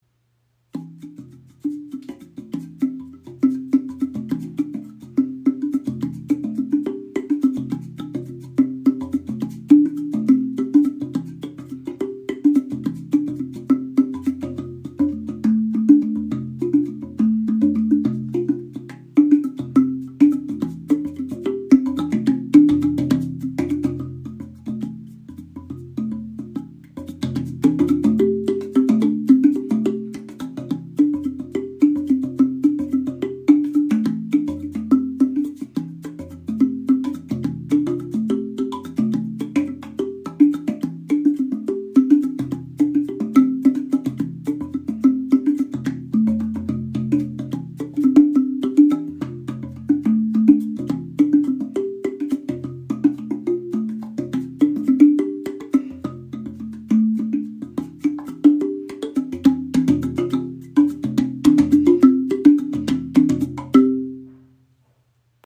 Die Baba Tong ist eine einzigartige Schlitztrommel mit zwei Spielflächen: tiefer Bass links, filigranes Fingerspiel rechts.
Klangbeispiel
Links ein tiefer Bass, rechts feine Töne für Fingerspiele. Intuitiv spielbar, sanft im Klang und handgefertigt aus edlem Holz.